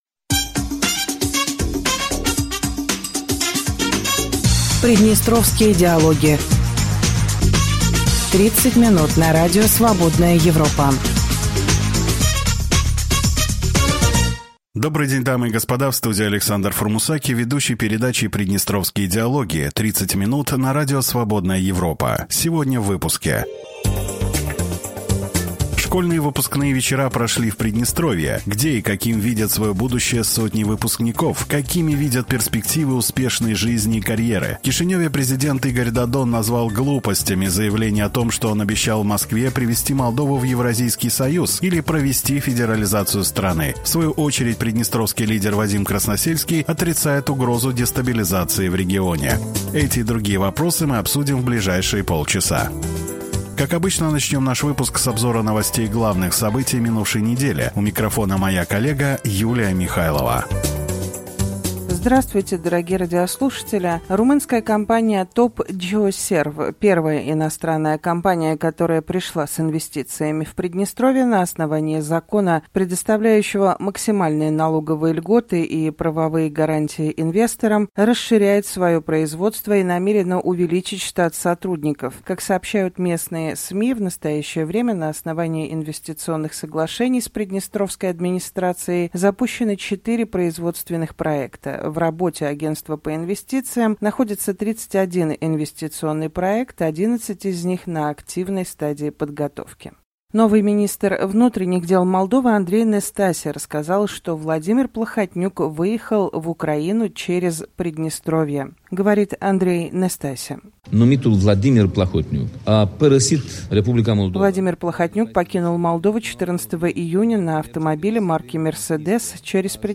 Дорогие радиослушатели, добрый день.